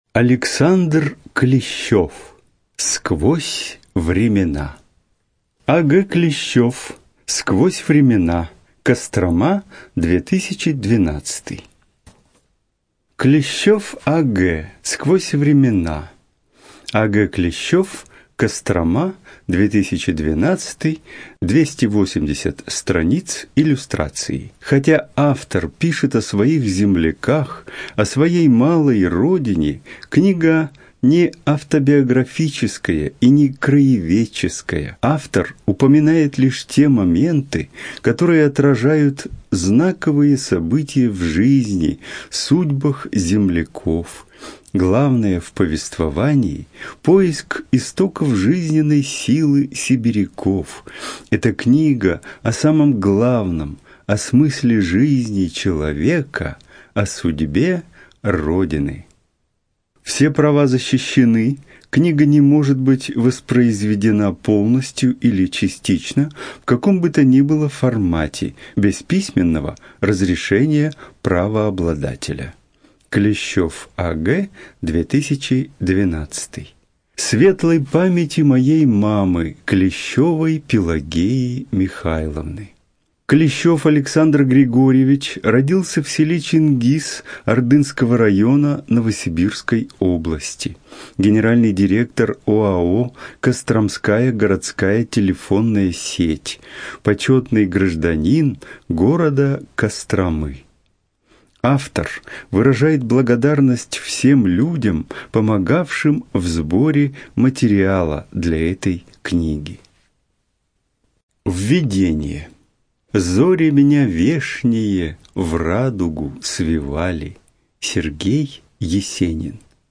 Студия звукозаписиНовосибирская областная специальная библиотека для незрячих и слабовидящих